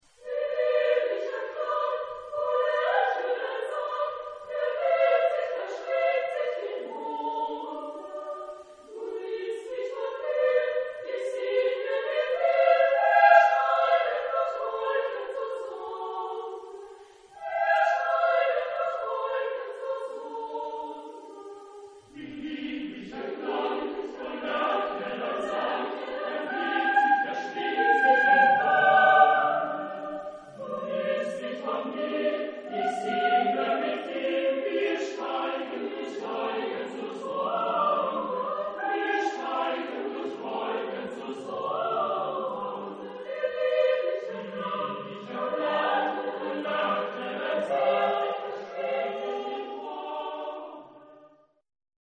Genre-Style-Forme : Romantique ; Profane ; Canon
Type de choeur : SATB  (4 voix mixtes )
Tonalité : fa majeur